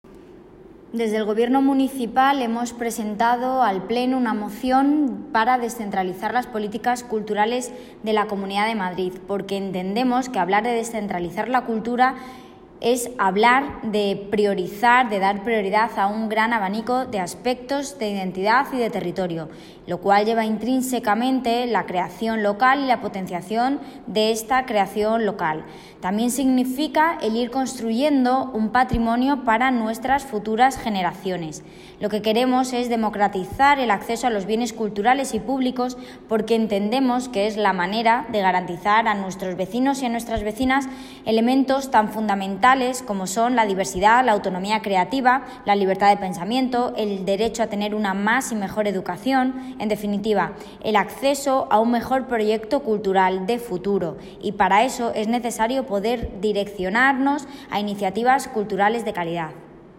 Audio - Jessica Antolín (Concejala Desarrollo Económico, Empleo y Nuevas Tecnologías) Sobre Moción Cultura